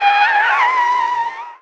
pgs/Assets/Audio/Vehicles_Engines_Motors/tyre_skid_06.wav at 7452e70b8c5ad2f7daae623e1a952eb18c9caab4
tyre_skid_06.wav